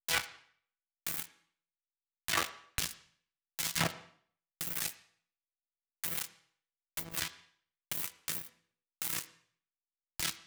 SFX_Static_Electricity_04.wav